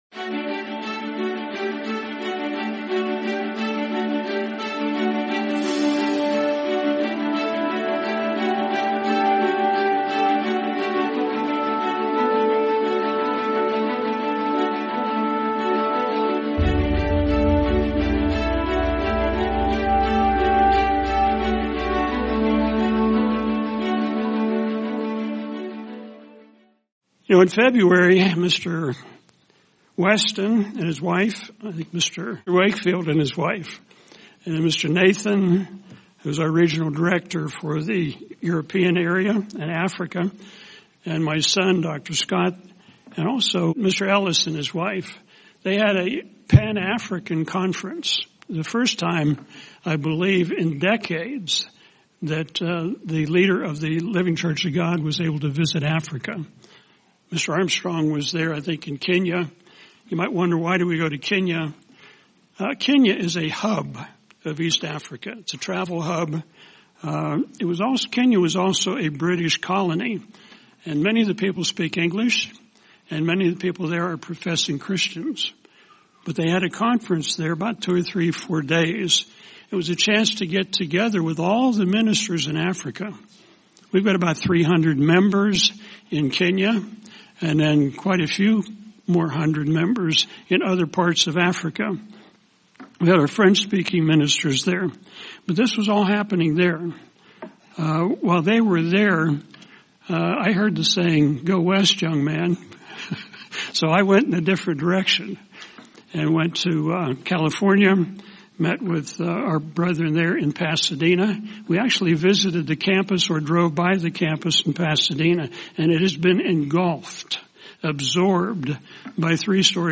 Sermon Why Are You Here?